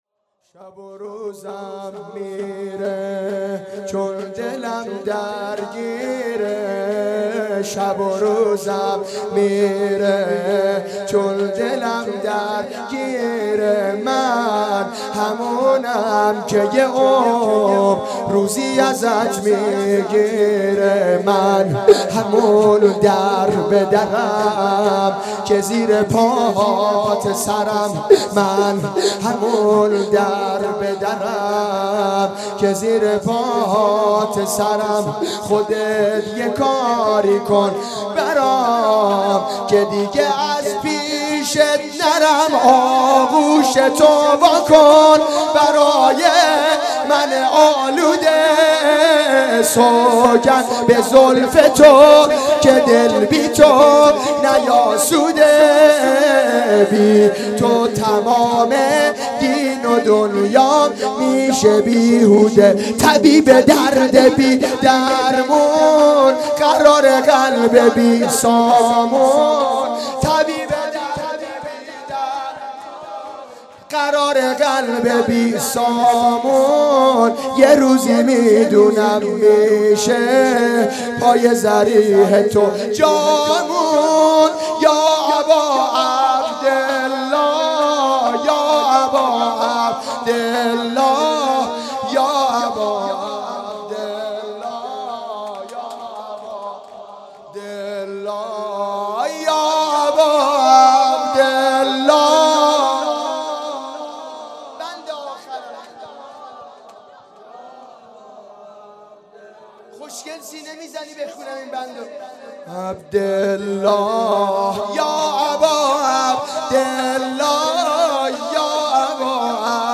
اقامه عزای شهادت امام حسن مجتبی علیه السلام